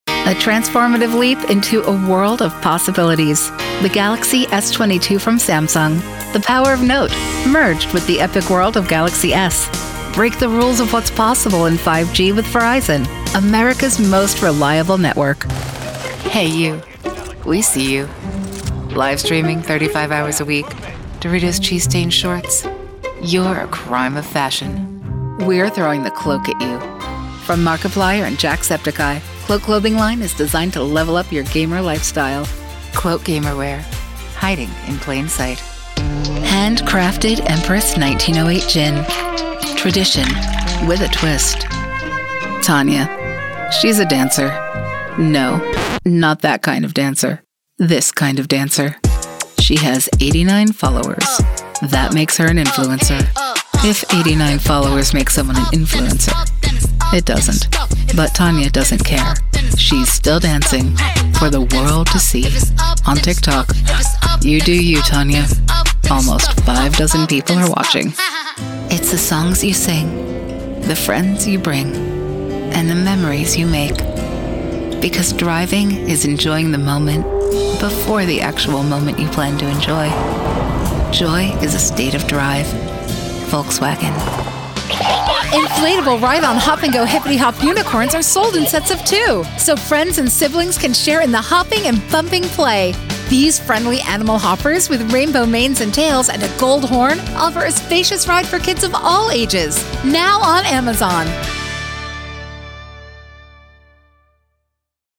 Voice Over Artist